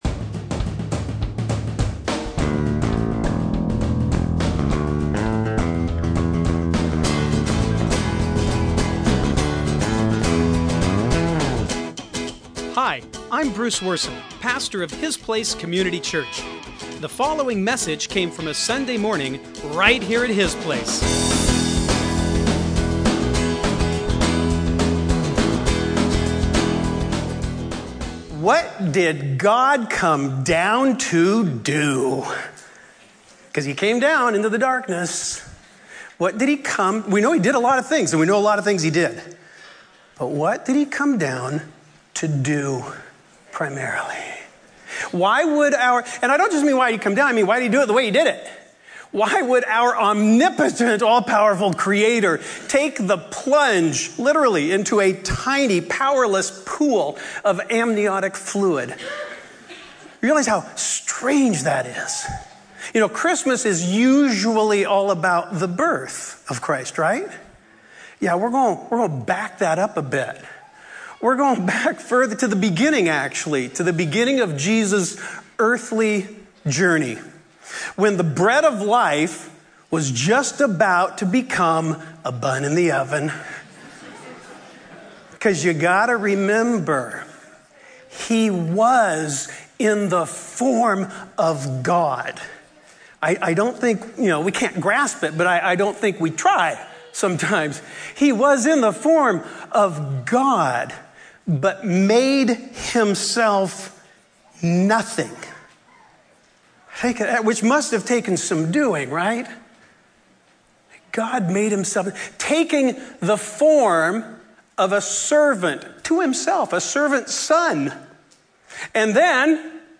The crucifixion isn’t just part of the Christmas story, it’s the reason for it. The “reason for the season” is Jesus’ birth; but the reason for His birth is His death. Christmas Eve Candlelight service.